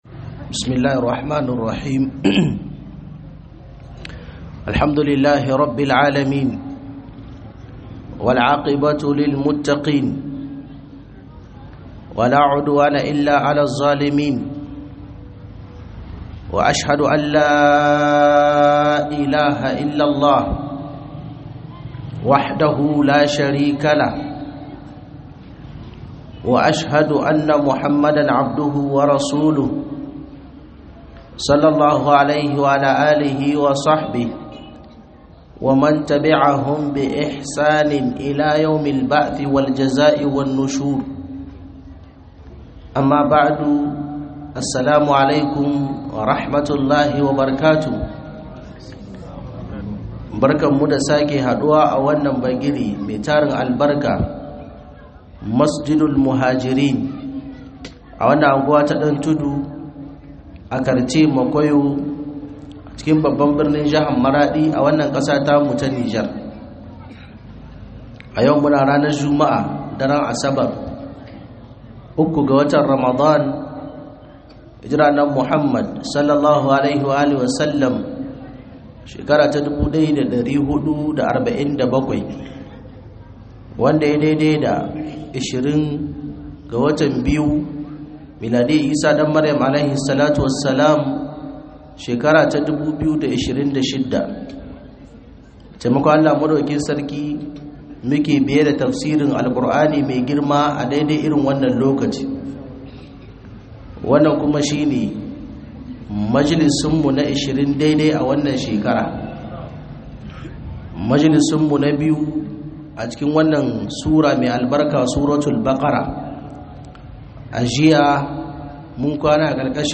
Tafsir Ramadan 1447H2026